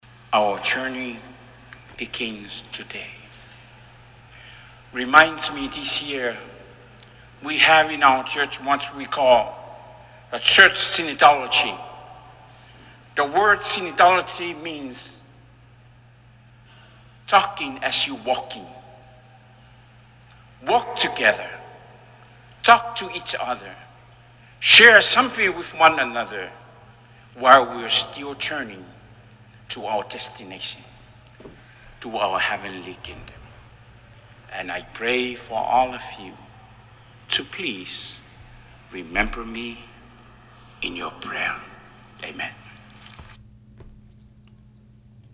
After the handover of the staff and whisk, the Bishop Kolio reflected on his motto, “Love is Sacrifice.”